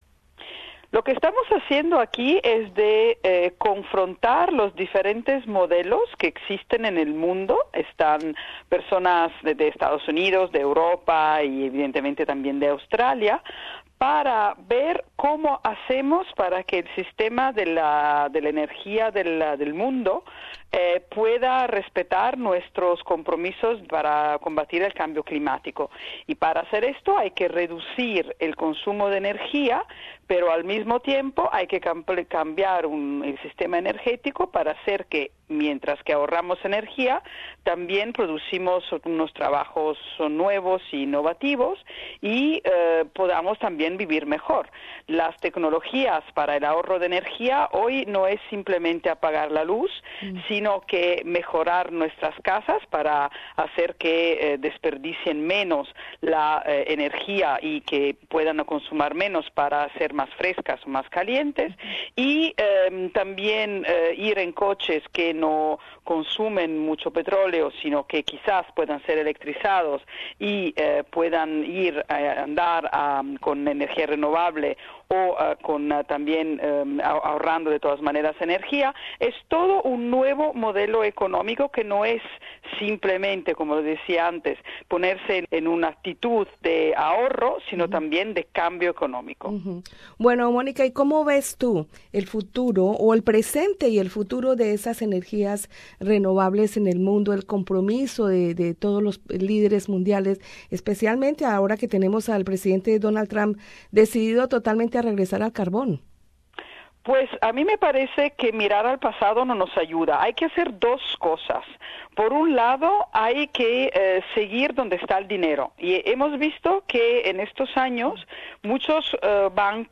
Entrevista con la presidenta de la Alianza Europea para el Ahorro de Energía (EUASE),Monica Frassoni, quien vino a Australia para participar en la Cumbre de Productividad de Energía 2017, 2xEP.